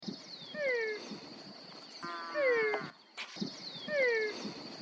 Ranita Llorona (Physalaemus albonotatus)
Nombre en inglés: Menwig Frog
Fase de la vida: Adulto
Localización detallada: Reserva Natural Urbana de General Pico (laguna La Arocena)
Condición: Silvestre
Certeza: Vocalización Grabada